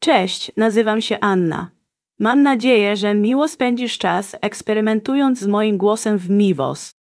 Synthetische Stimmen waren noch nie so einfach